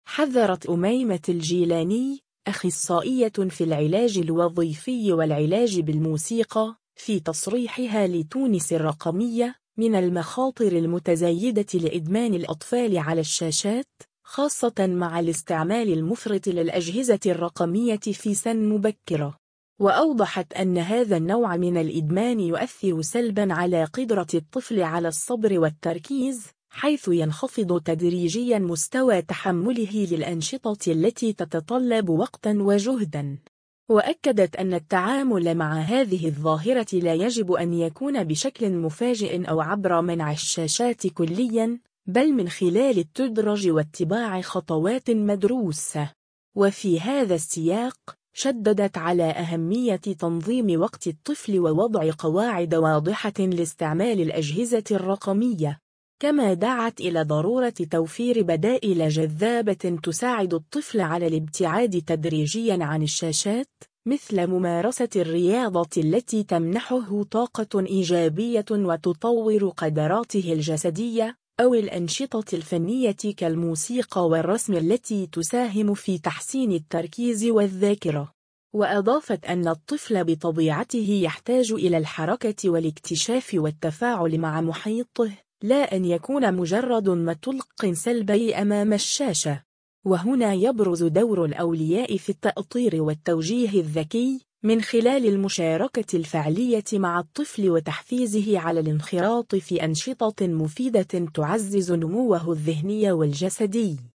أخصائية في العلاج الوظيفي والعلاج بالموسيقى